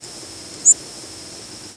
Zeep calls
N. Waterthrush
The calls are short, typically between 1/20th and 1/10th of a second (50-100 mS) in duration, and high-pitched, typically between 6-10 kHz. The calls have an audible modulation that gives them a ringing, buzzy, or sometimes trilled quality.
Another caveat here is that some species typically give rising calls (e.g., Northern Waterthrush) while other species give rising calls only in certain behavioral situations such as when they take off for morning flight.